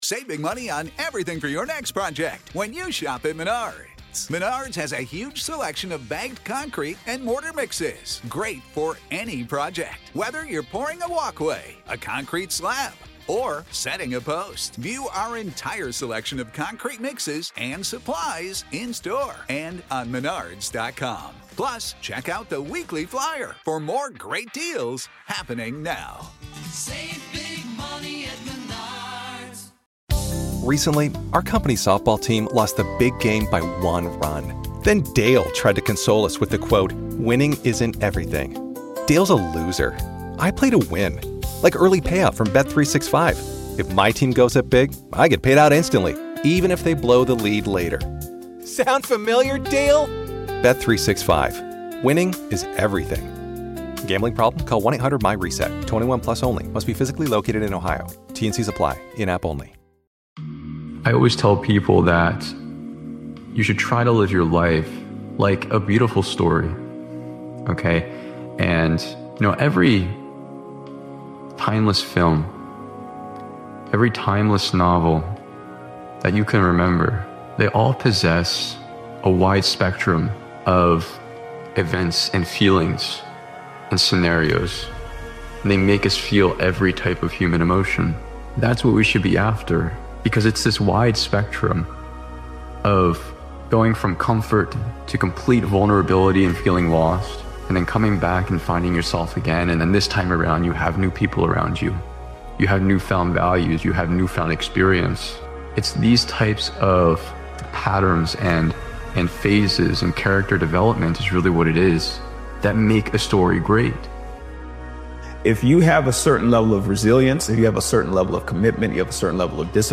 This powerful motivational speech by Daily Motivations is about making a decision to change and sticking to it. It focuses on discipline, consistency, accountability, and the mindset required to break old habits and stop repeating the same patterns.